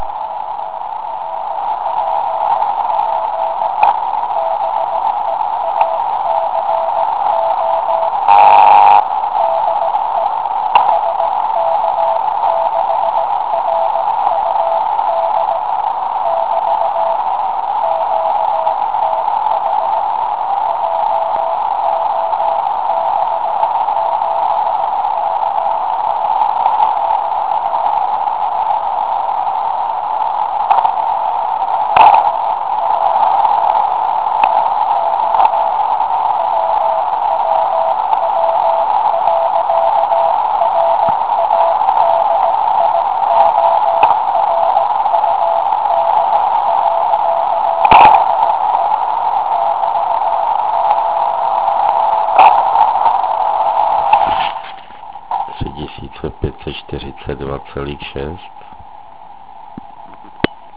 Jeden ze zajímavých majáků pracuje na 3542.6 KHz